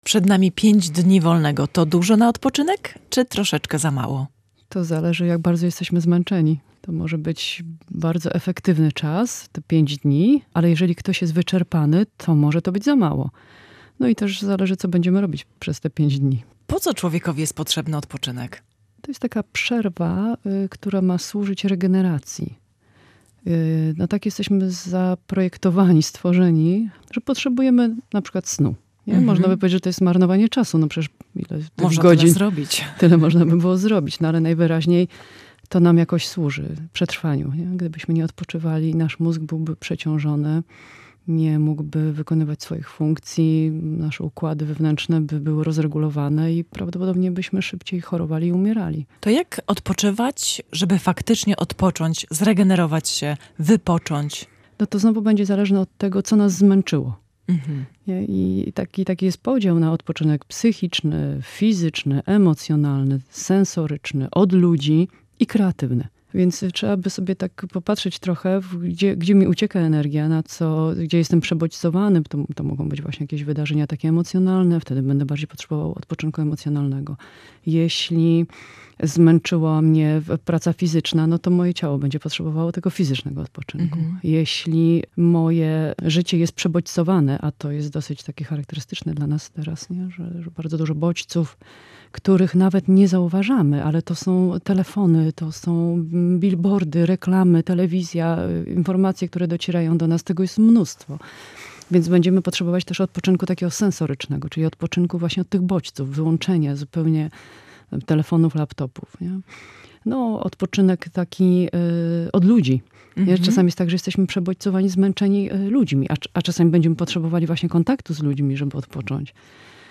Majówkę rozpoczynamy od rozmowy o odpoczynku.